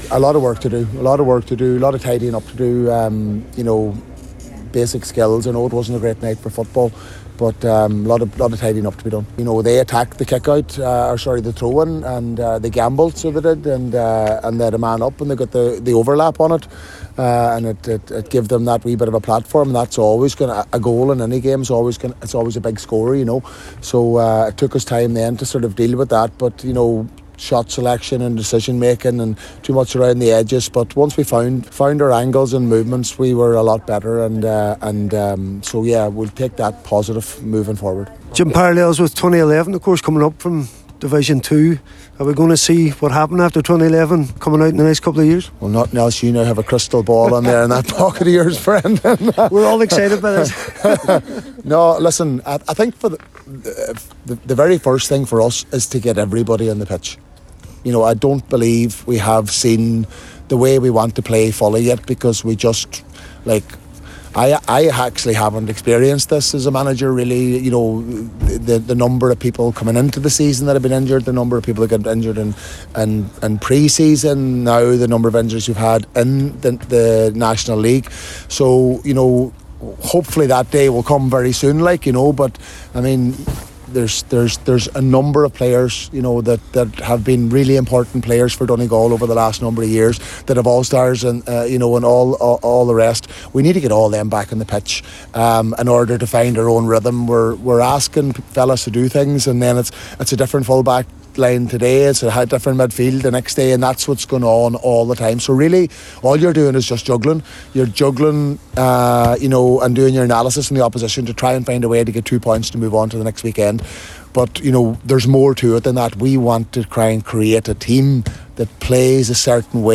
after the full time whistle in Ballybofey.